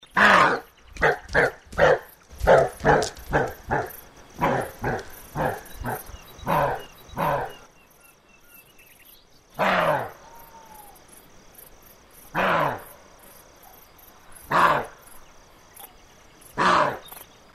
С помощью интернета удалось идентифицировать голос ночного зверя, он больше всего оказался похож на
лай косули.